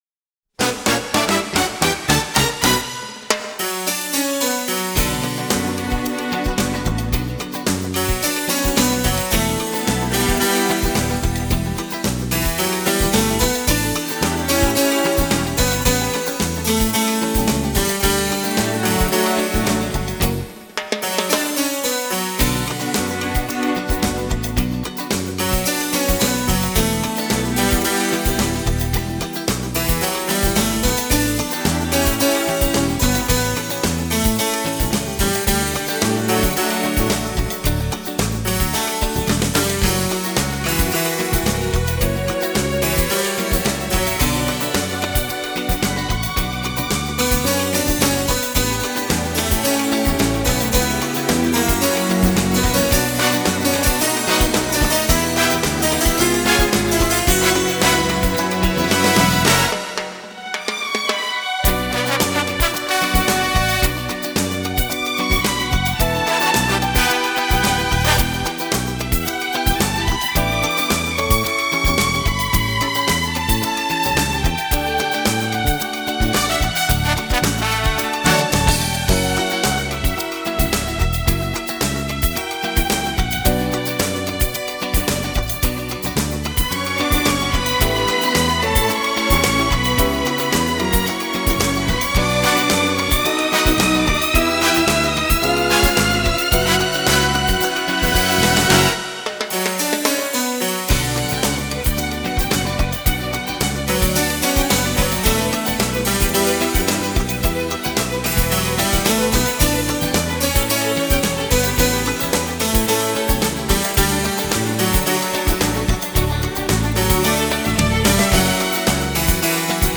instrumental music
موسیقی بی کلام